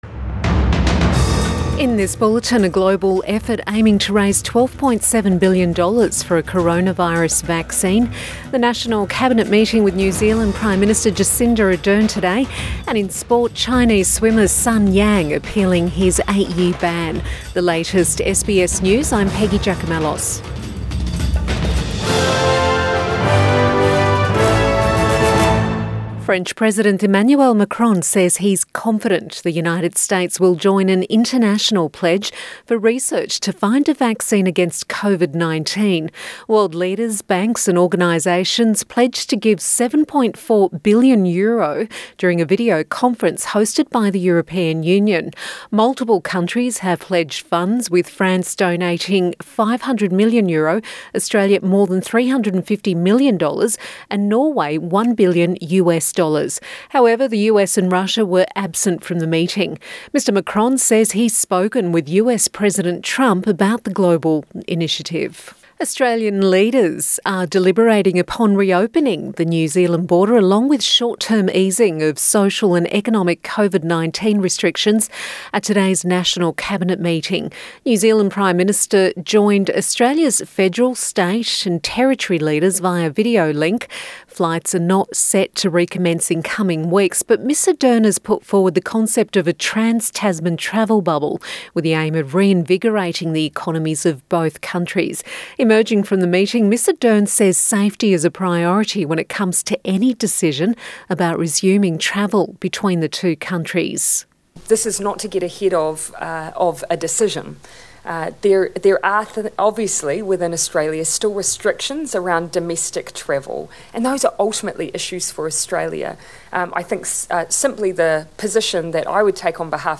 Midday bulletin May 5 2020